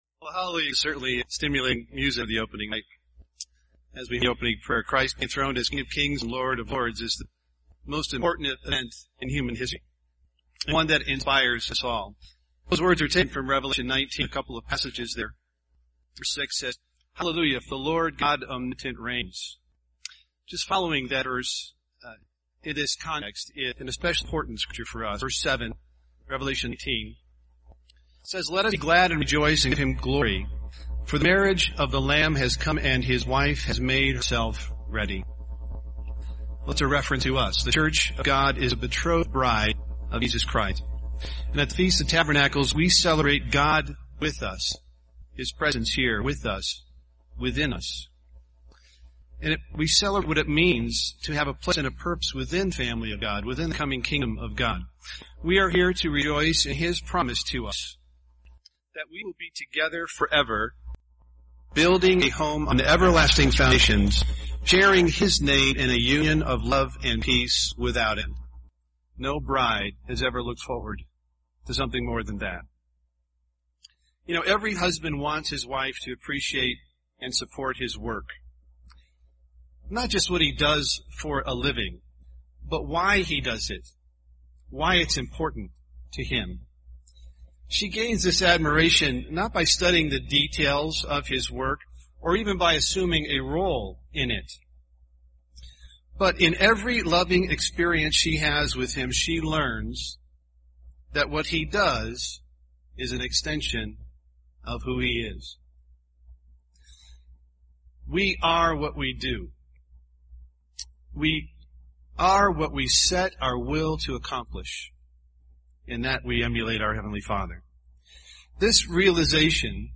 This sermon was given at the Wisconsin Dells, Wisconsin 2012 Feast site.